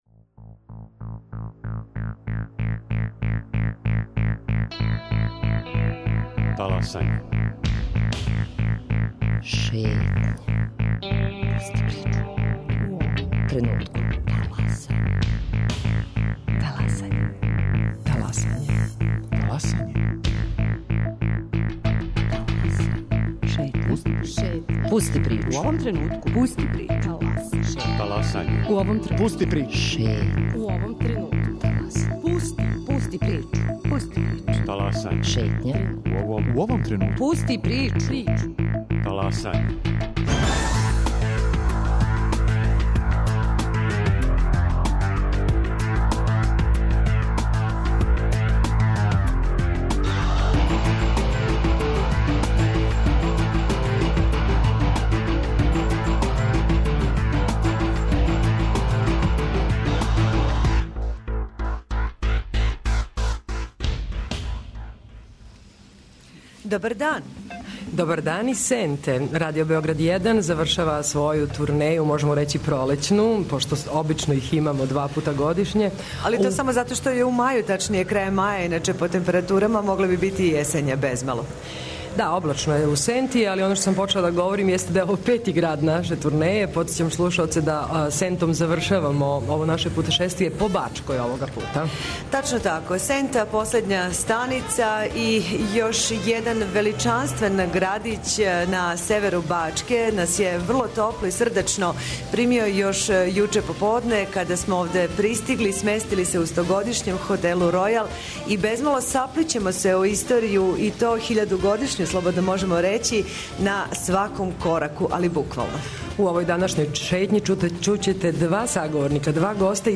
Гости овог издања Шетње, коју емитујемо уживо из Сенте су председник општине Рудолф Цегледи